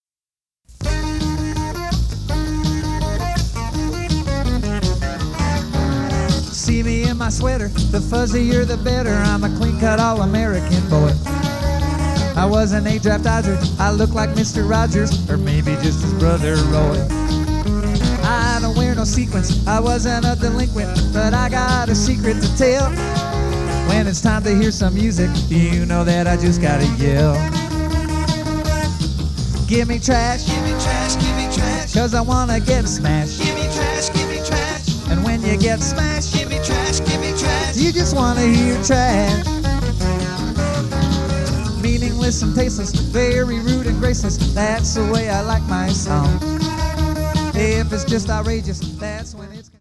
on an old Teac 4-track, reel-to-reel recorder.